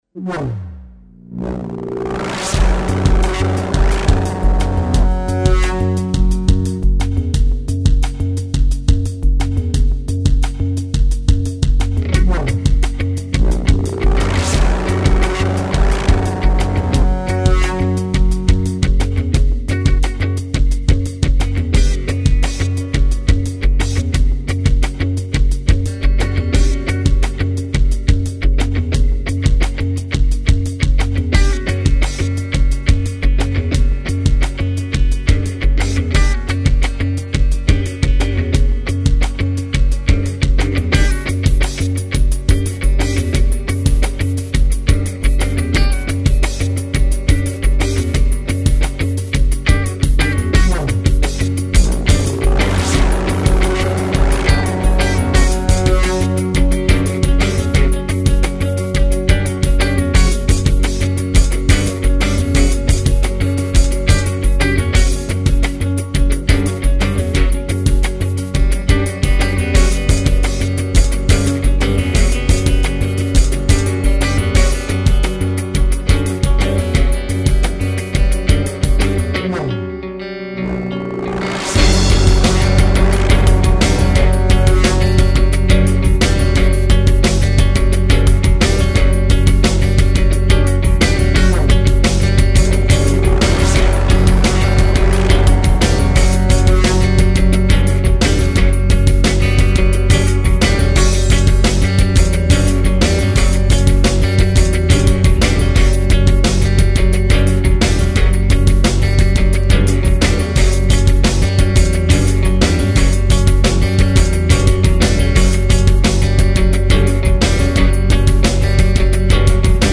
crossover of eletronics with rock roots